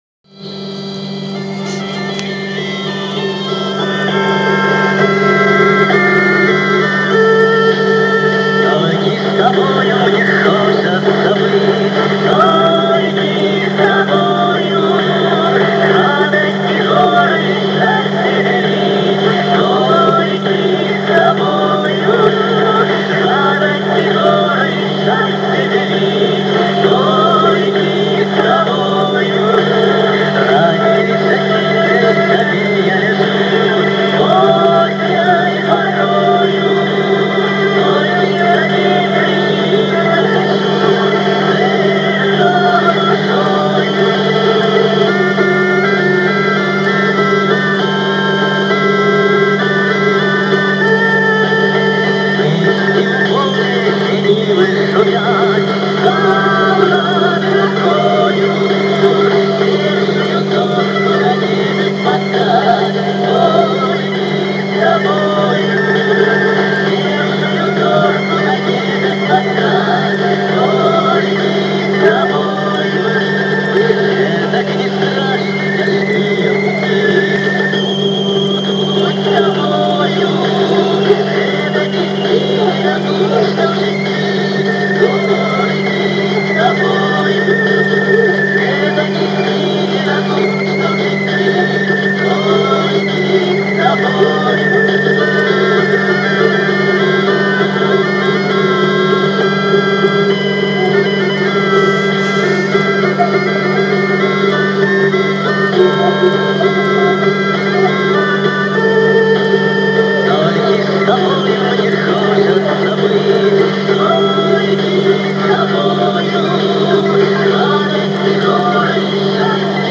Здесь немного изменяла  тон и звук.